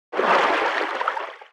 Sfx_creature_seamonkeybaby_swim_slow_01.ogg